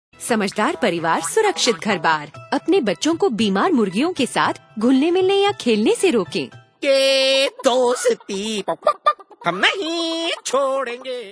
This is first in the series of five Radio PSA, address backyard farmers and their families. It uses a performer and a rooster puppet as a creative medium to alert families to poultry diseases and instill safe poultry behaviours.
Radio PSA